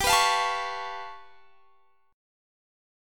G9b5 Chord
Listen to G9b5 strummed